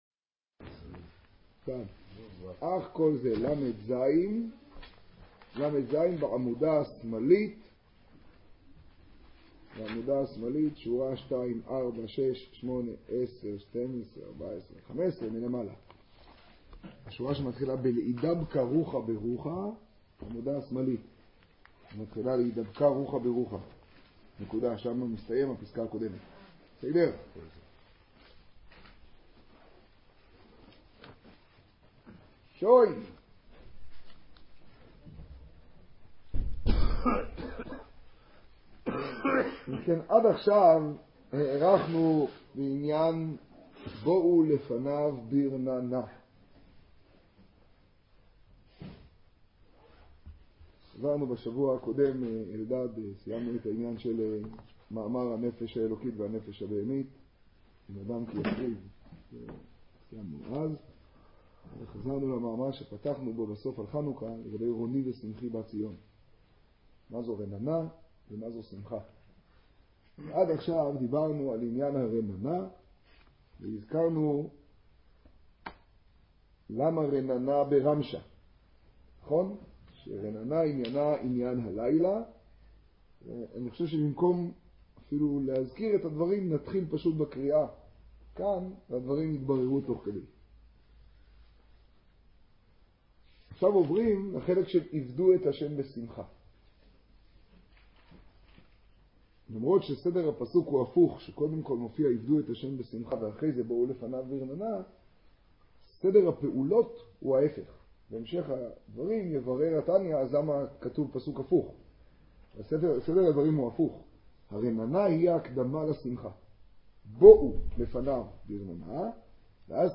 תורה אור, פרשת מקץ – טקסט המאמר שיעור 1 שיעור 2 שיעור 3 שיעור 4 קטגוריה: אחר , שיעור , תוכן תג: אדמור הזקן , חנוכה , תשע → קטונתי – כדאי אני.